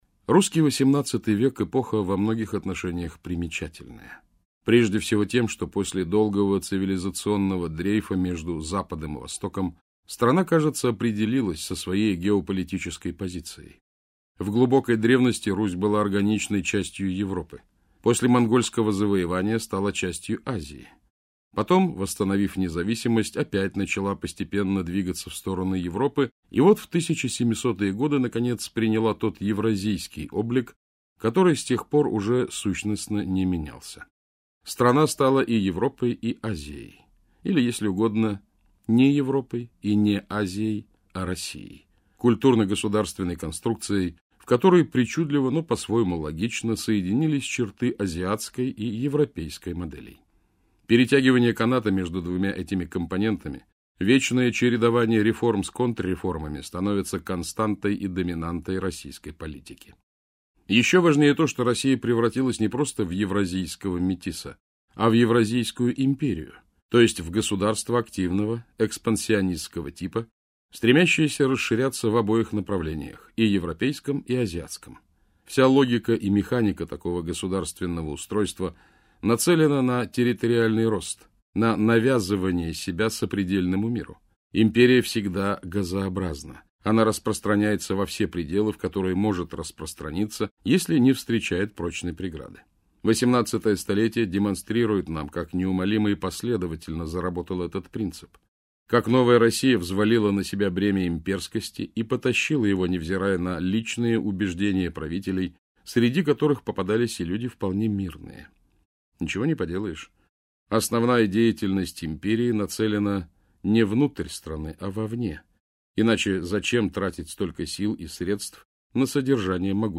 Аудиокнига Евразийская империя. История Российского государства. Эпоха цариц - купить, скачать и слушать онлайн | КнигоПоиск